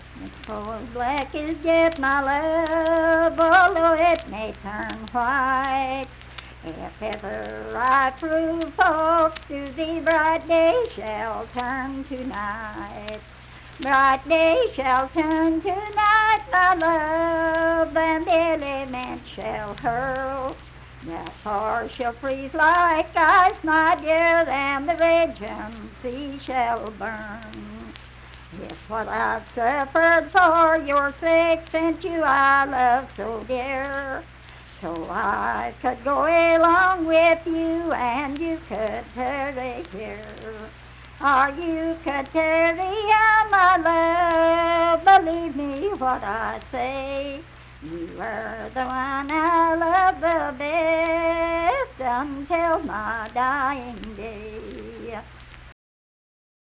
Unaccompanied vocal music performance
Verse-refrain 3(4) & R(4).
Voice (sung)